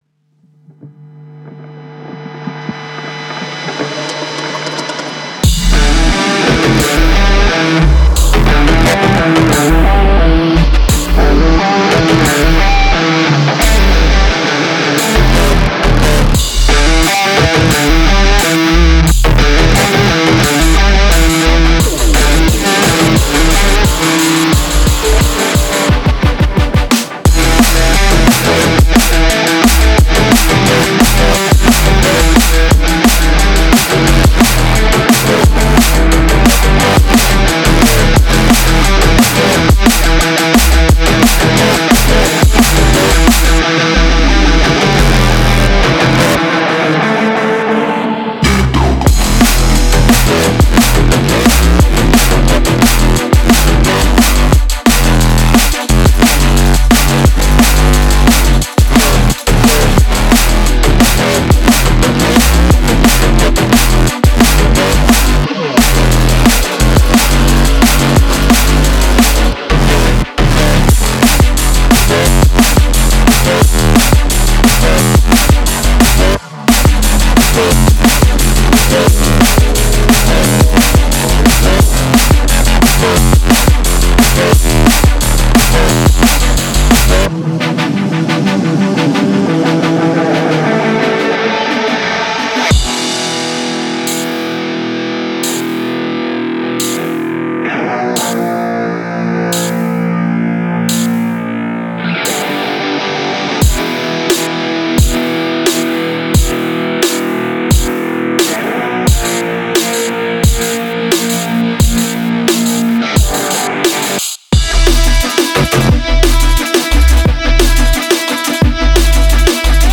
Релизы Drum and Bass Нижний Новгород